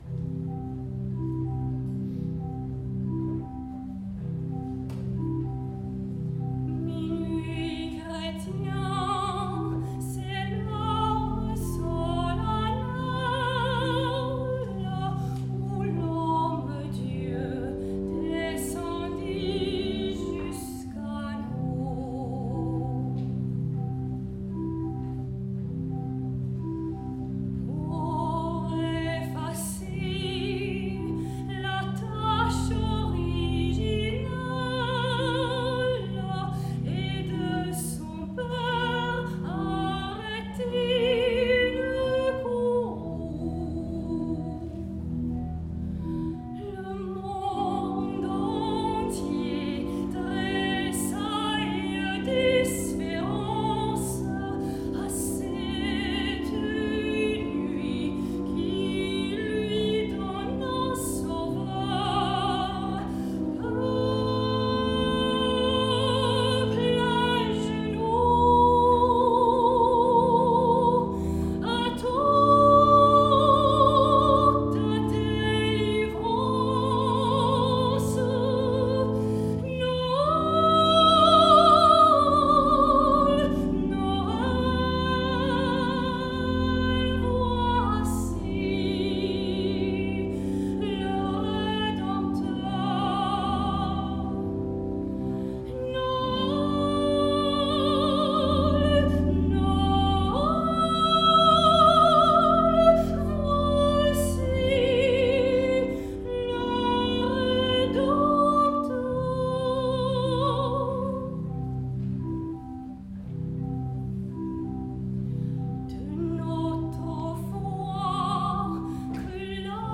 concert de Noël – église Ste Croix de Lorry
Minuit chrétiens (Adolphe Adam, Placide Cappeau, XIXe siècle)   Voix et Orgue